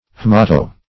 Haemato- \H[ae]m"a*to-\ (h[e^]m"[.a]*t[-o]- or h[=e]"-), prefix.
haemato-.mp3